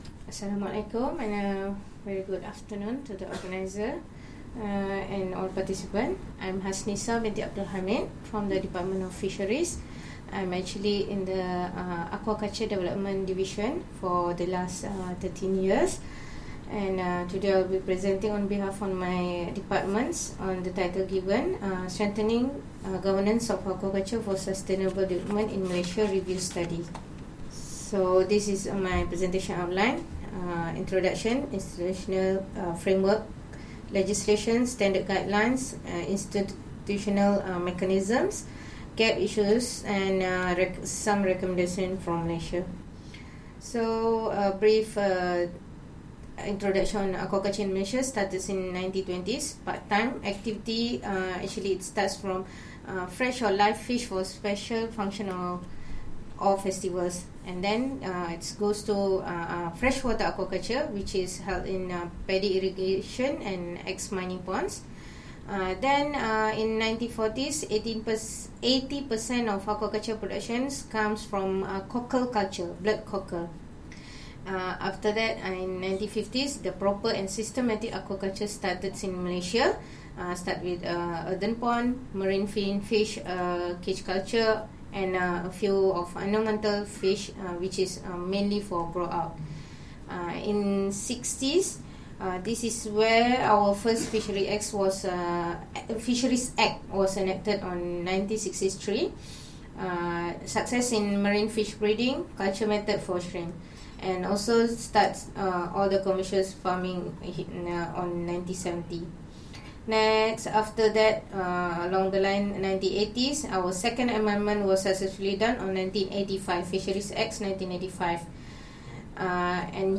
Audio recording of presentation delivered at the Consultation on Strengthening Governance of Aquaculture for Sustainable Development in Asia-Pacific, 5-6 November 2019, Bangkok, Thailand.